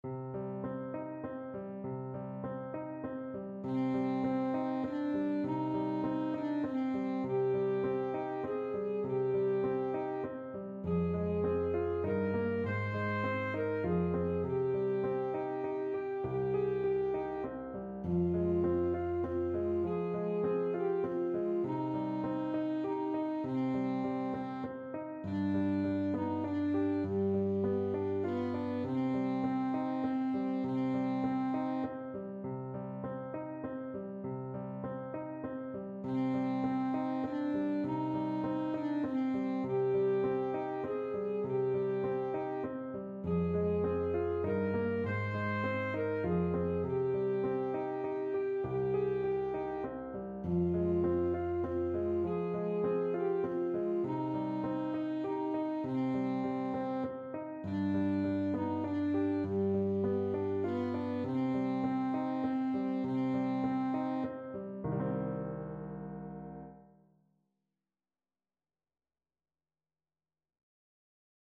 Alto Saxophone
3/4 (View more 3/4 Music)
Moderato, gently
Classical (View more Classical Saxophone Music)